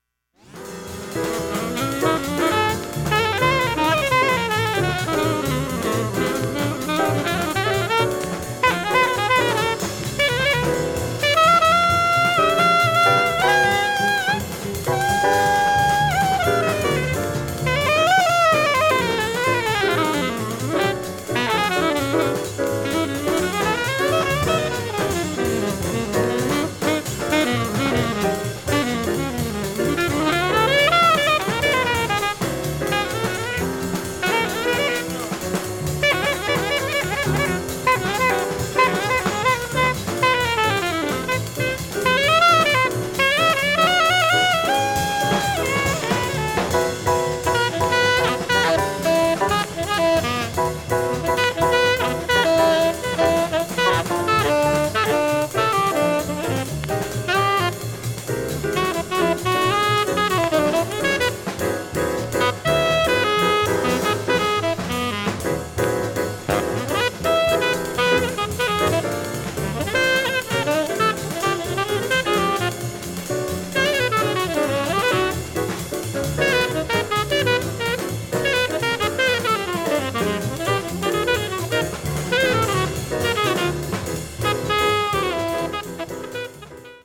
盤面きれいです音質良好全曲試聴済み
９０秒の間に周回プツ出ますがかすかで、
ほとんど聴き取れないレベルです。
◆ＵＳＡ盤 Repress, Stereo